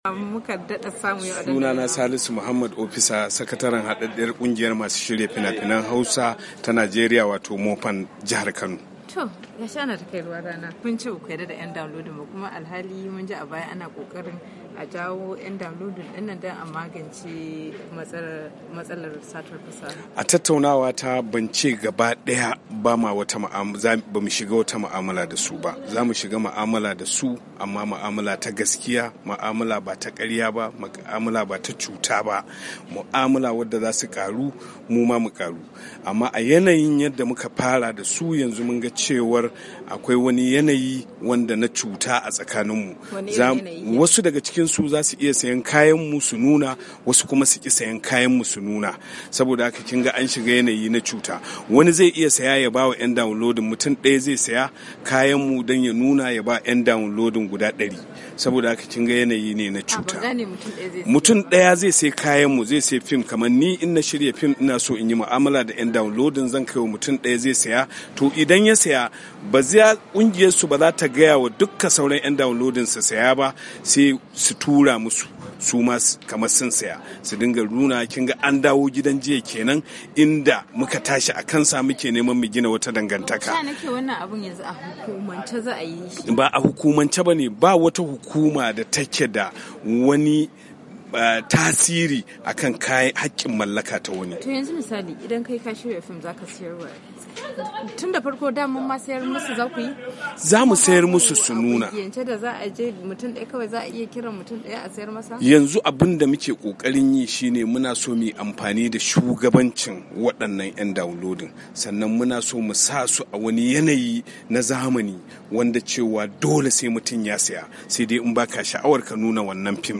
Ya bayyana haka ne a yayin da yake zantawa da wakiliyar DandalinVOA inda ya ce akwai yiwuwar wasu daga cikin ‘yan downloading din ba zasu sami damar sayar da fim dinsu ba, kuma ba zasu bi ka'idojin da aka gindaya masu ba domin da zarar wani babban dan downloading ya sami sayen wani fim, zai iya raba shi ga sauran ‘yan downloading ba tare da sun biya kudin saye ba, a cewarsa hakan nakasu ne ga yarjejeniyar da zasu kulla.